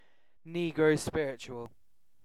Uttal
Synonymer spiritual Uttal UK Okänd accent: IPA : /ˈni.ɡɹoʊˌspɪɹ.ə.tʃu.əl/ IPA : /ˈniː.ɡɹəʊˌspɪɹ.ɪ.tʃu.əl/ Ordet hittades på dessa språk: engelska Ingen översättning hittades i den valda målspråket.